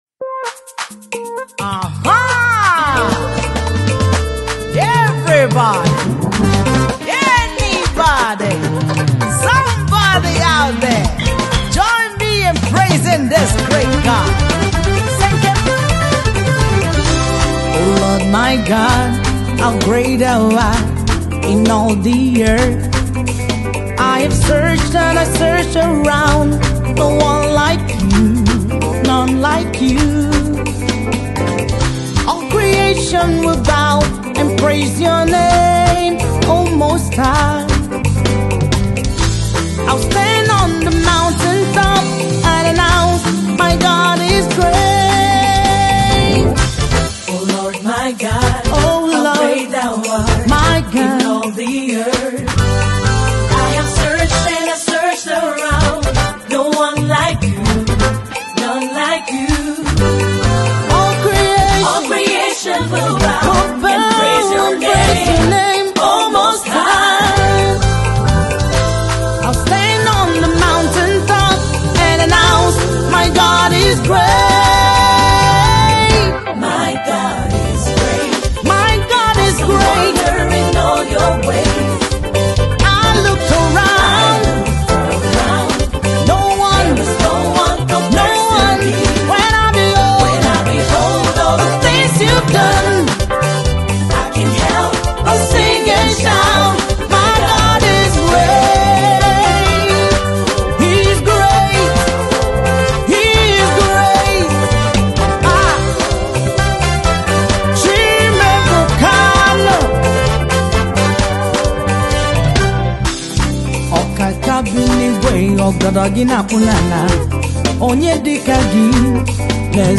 Gospel
groovy song
a fusion of Igbo and English